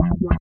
88 BS LICK-R.wav